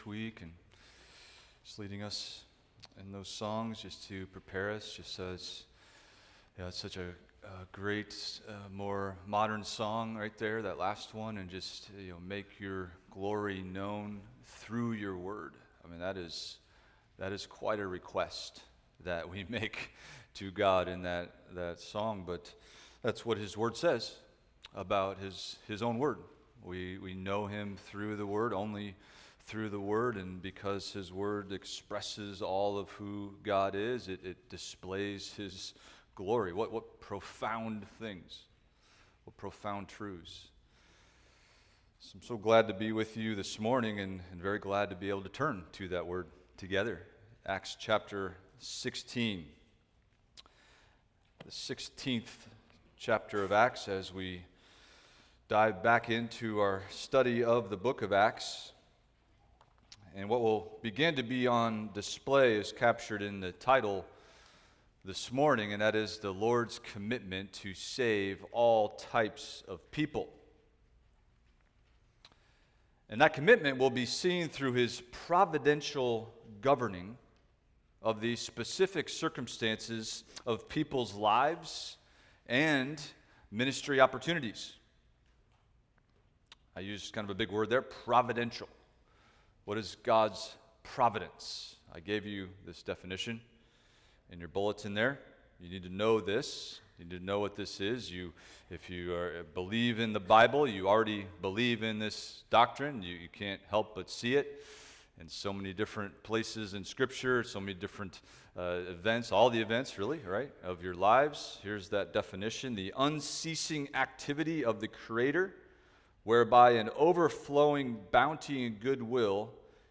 Expository Preaching through the Book of Acts